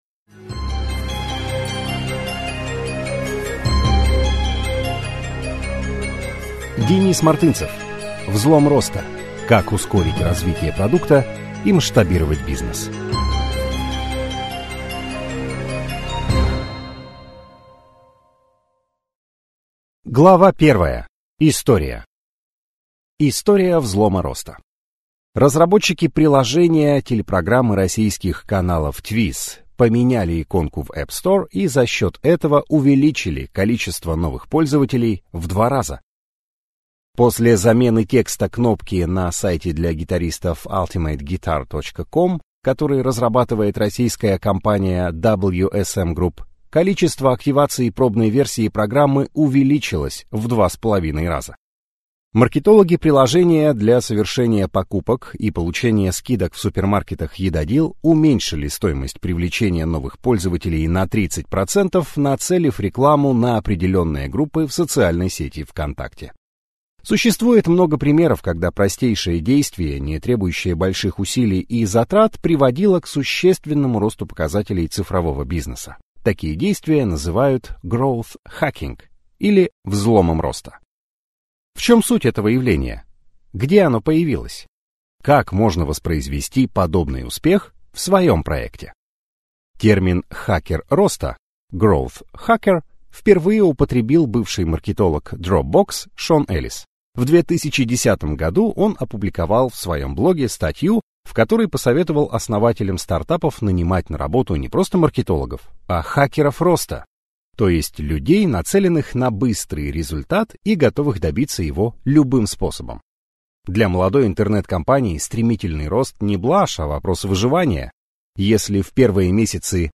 Аудиокнига Взлом роста. Как ускорить развитие продукта и масштабировать бизнес | Библиотека аудиокниг